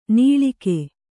♪ nīḷike